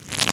shuffle.wav